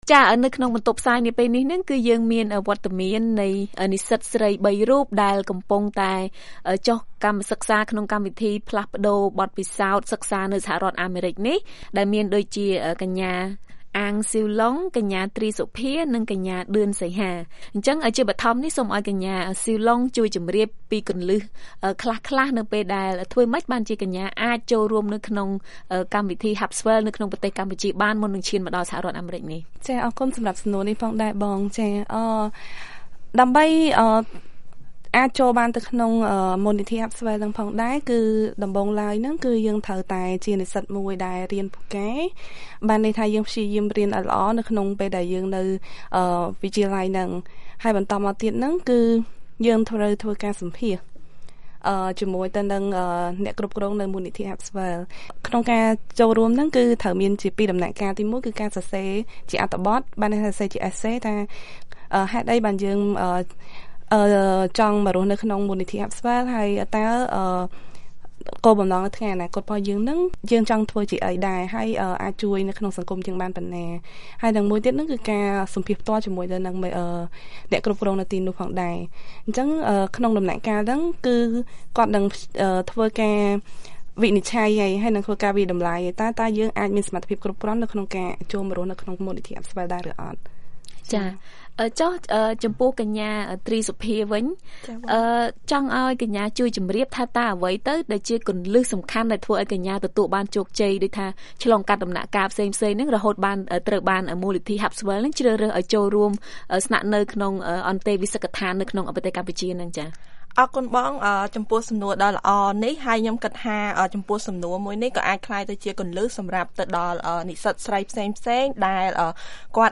បទសម្ភាសន៍ VOA ៖ និស្សិតកម្មវិធី Harpswell ចែករំលែកគន្លឹះទទួលបានអាហារូបករណ៍